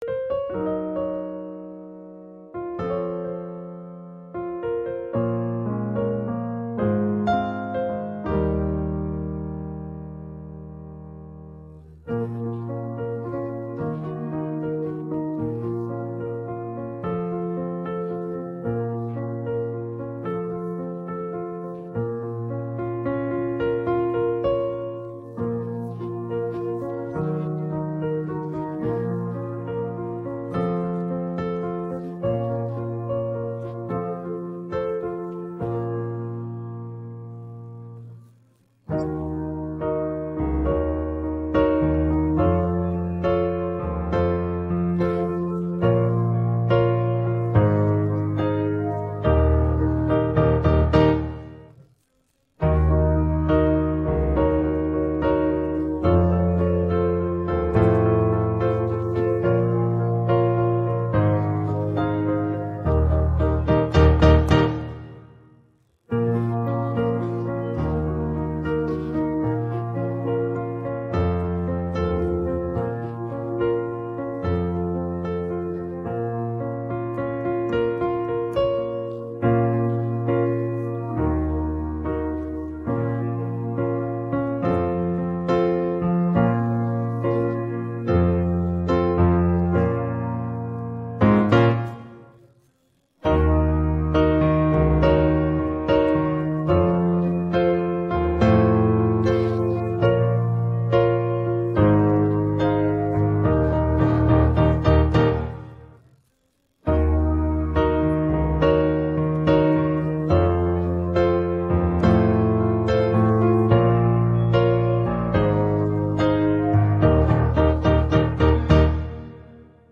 guitar караоке 4